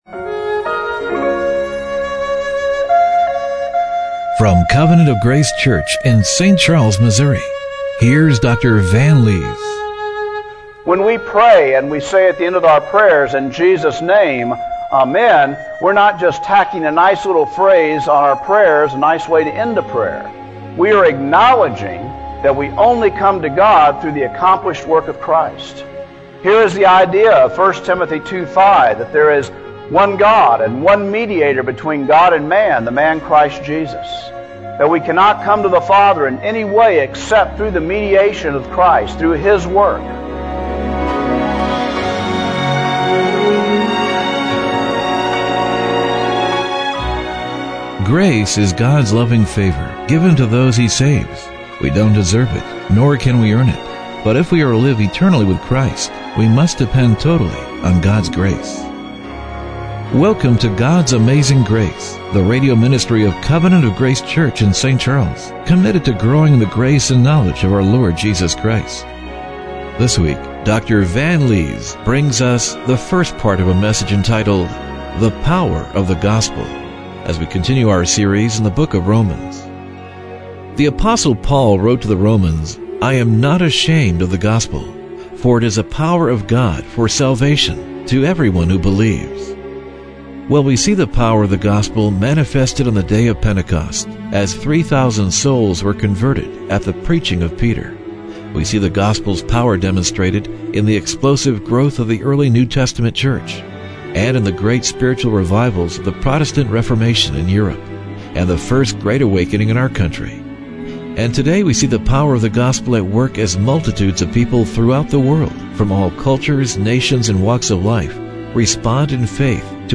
Service Type: Radio Broadcast